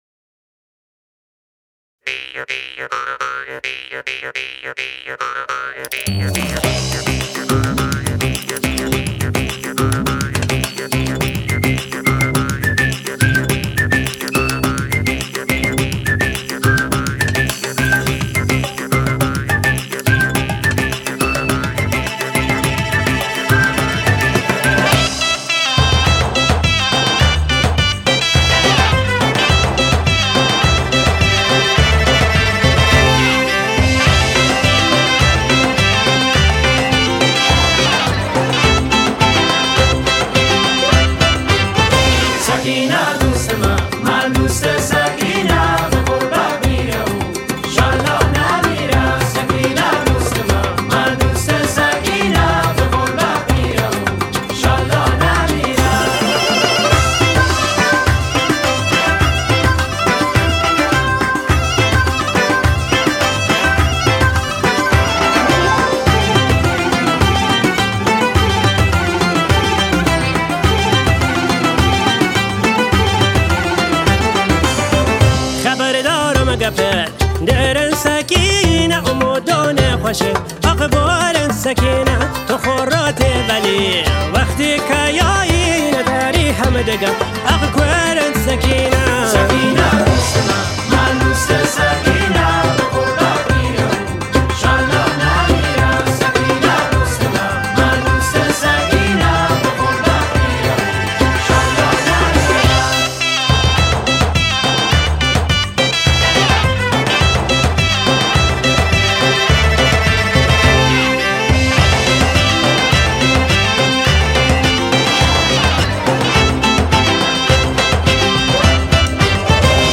南東部ケルマーン州の歌